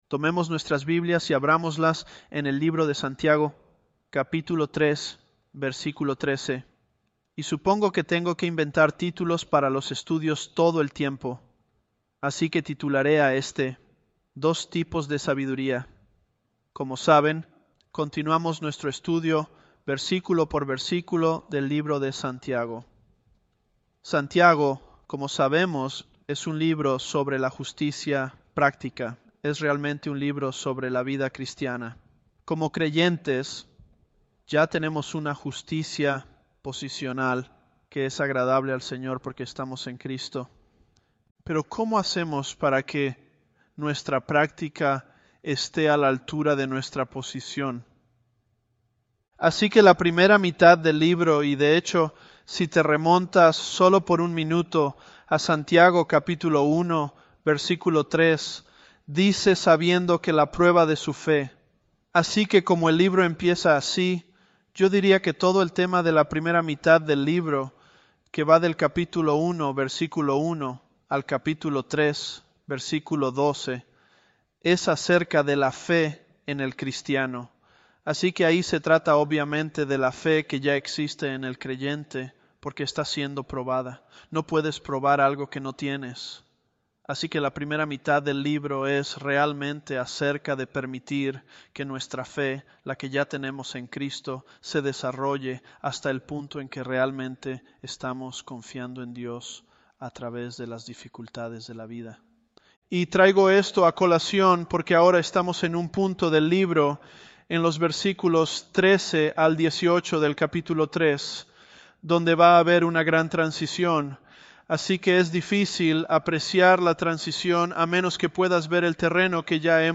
ElevenLabs_James018.mp3